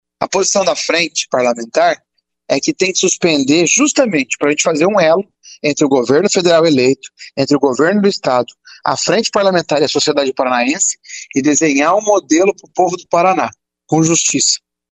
Player Ouça deputado Arilson Chiorato (PT)